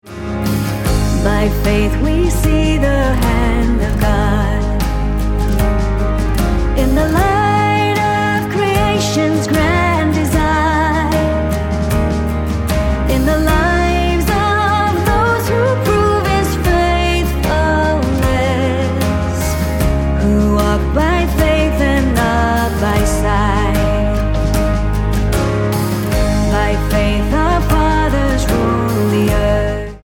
Ab